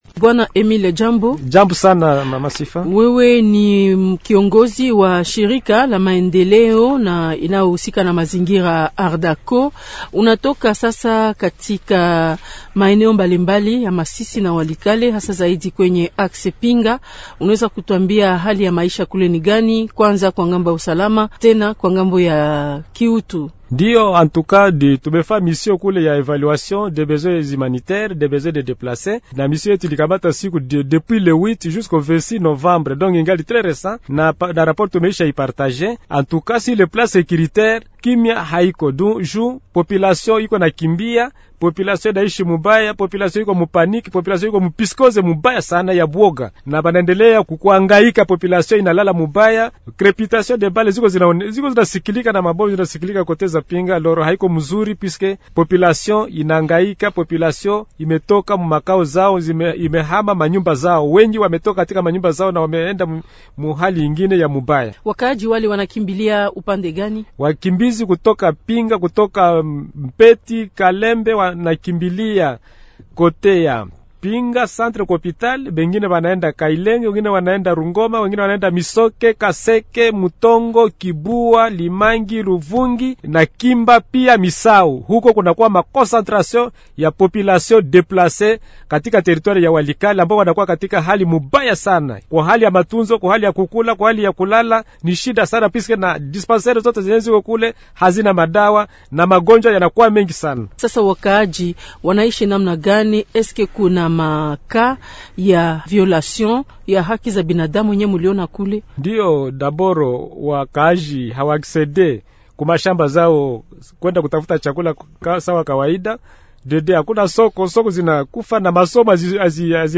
Yeye ndiye mgeni wetu leo. Anazungumzia matokeo yake juu ya viwango vya usalama, kibinadamu na afya.